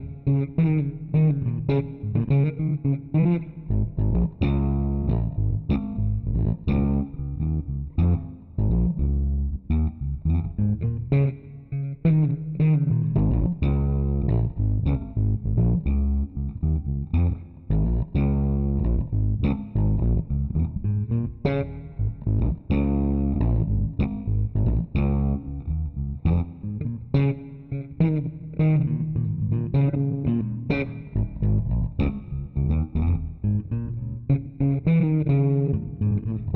标签： 105 bpm Rock Loops Drum Loops 6.15 MB wav Key : Unknown
声道立体声